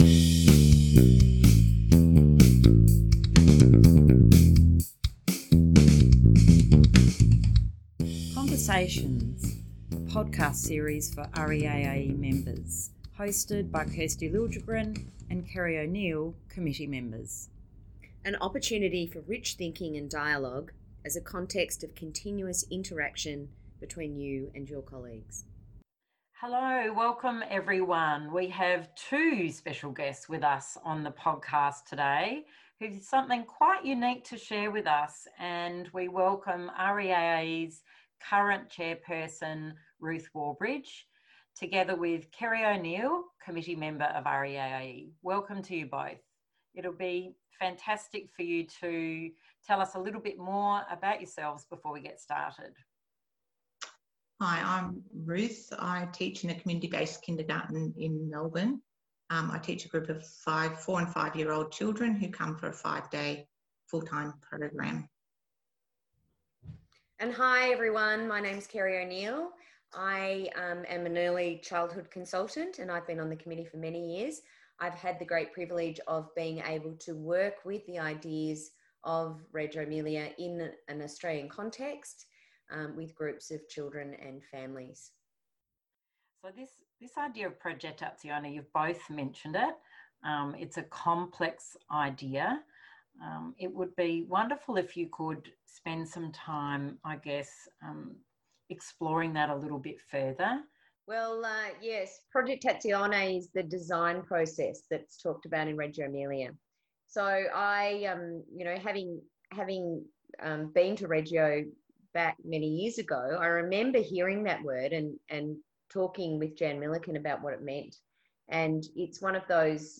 It is an opportunity for rich thinking and dialogue, as a context of continuous interaction, between you and your colleagues.